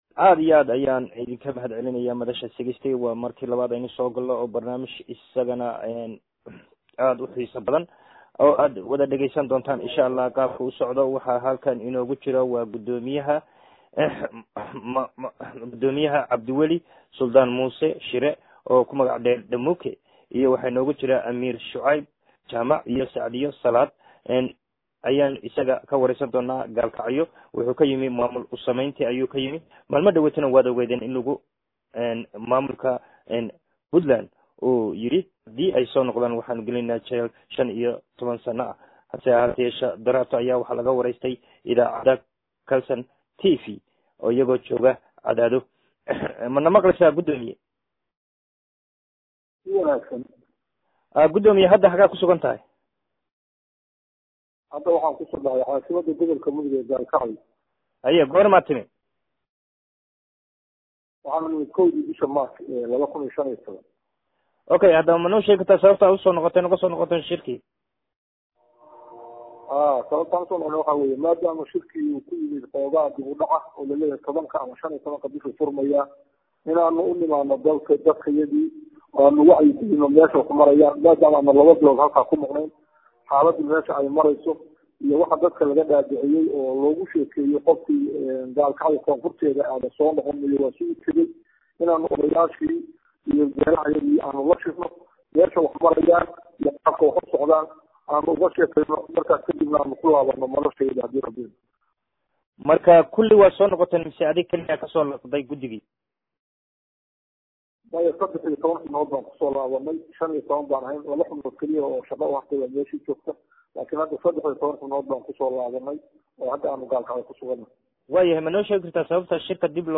Hadaba Xalay oo xogbadan la helay dhagayso waraysigaa laga qaaday sadexda Masuul.